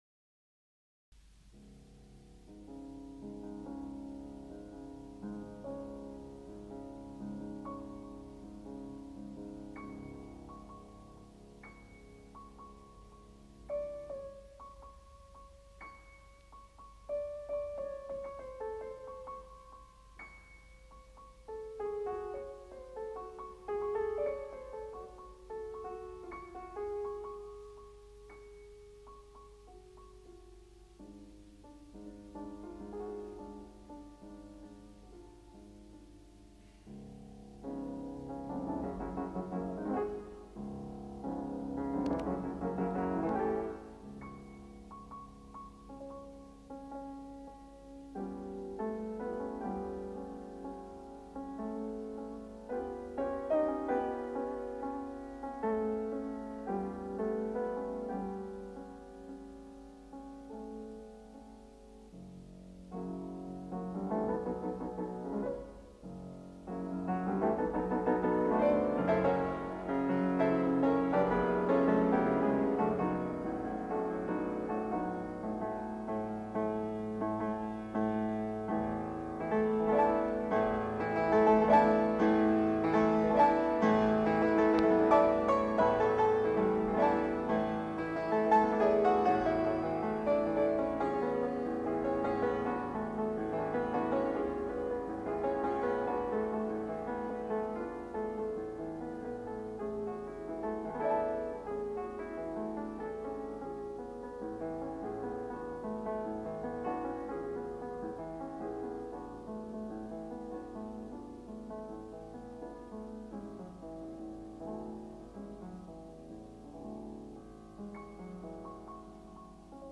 La Soirée dans Grenade» de «Estampes», interpretado aquí por la gran pianista Alicia De Larrocha…
Alicia-de-Larrocha-plays-Debussy-La-soiree-dans-Grenade-1972-Live_1.mp3